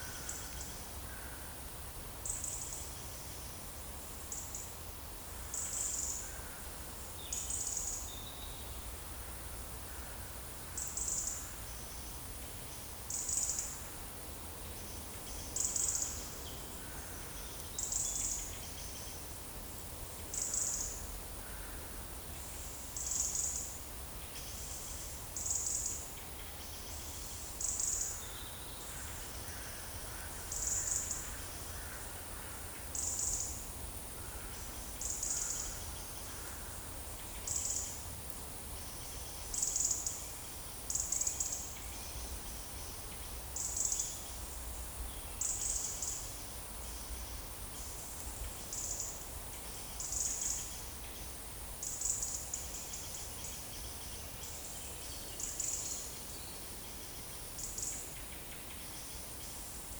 Monitor PAM - Renecofor
Turdus merula
Troglodytes troglodytes
Erithacus rubecula
Turdus viscivorus